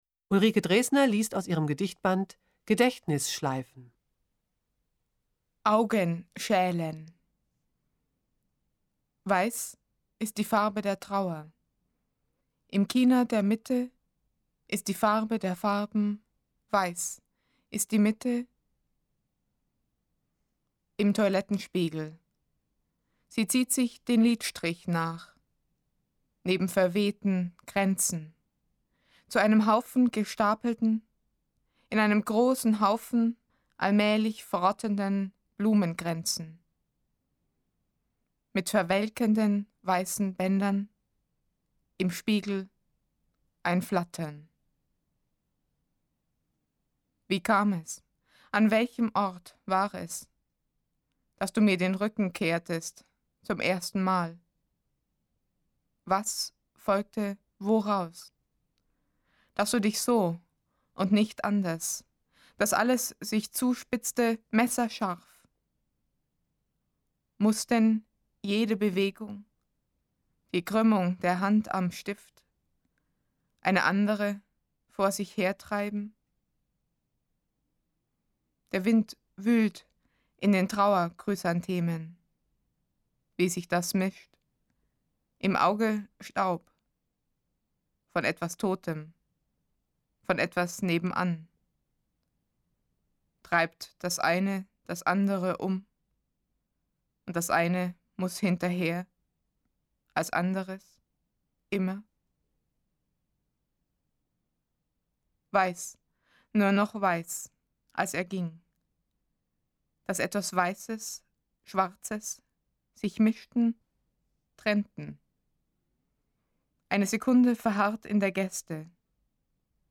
Es umfasst 40 CDs, auf denen insgesamt 573 Lesungen enthalten sind.